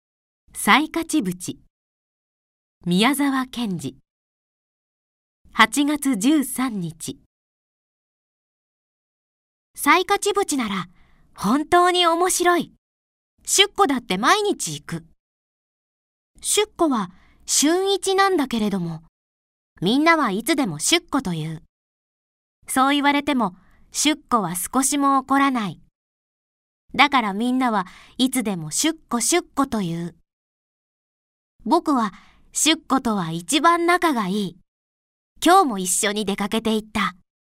朗読ＣＤ　朗読街道136
朗読街道は作品の価値を損なうことなくノーカットで朗読しています。